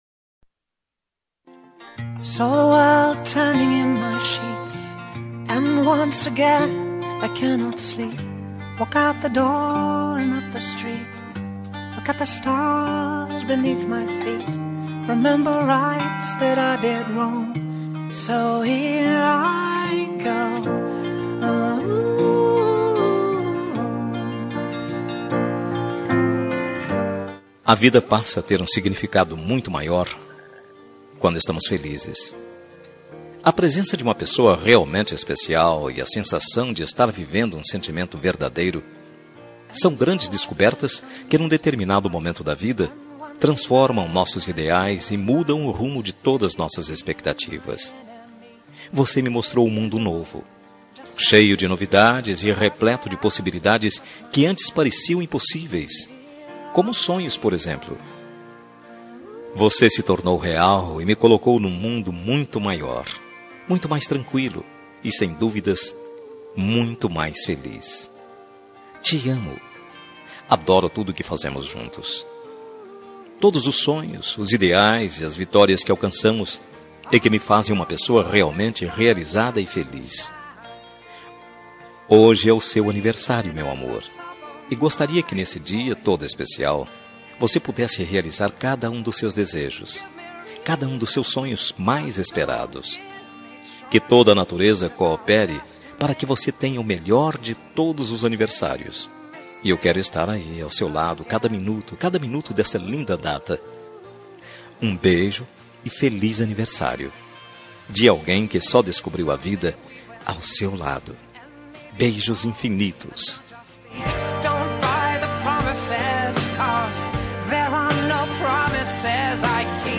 Telemensagem de Aniversário de Esposa – Voz Masculina – Cód: 3005